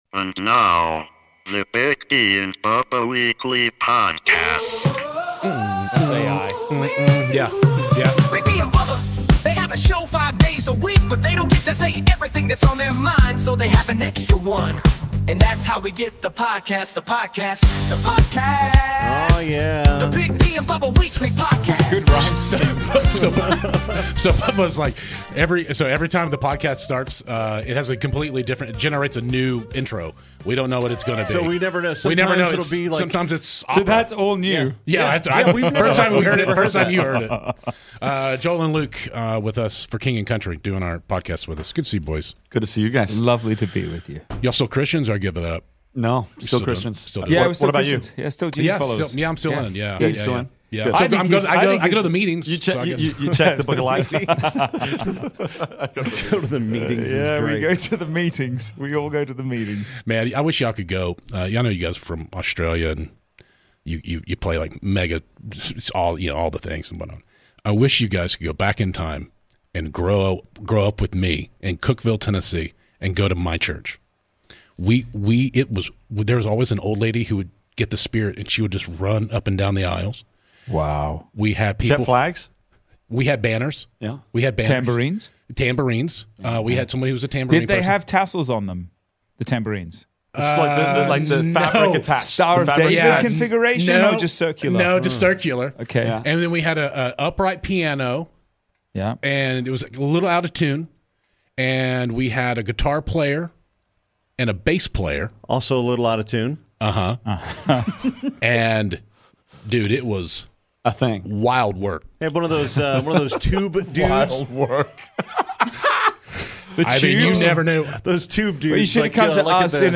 Weekly Podcast #536 - A highly entertaining, wide-ranging conversation with Grammy winners for KING + COUNTRY! Topics include their native Australia, "9-1-1 Nashville", Cracker Barrel, Stryper, automated cars, and SO much more!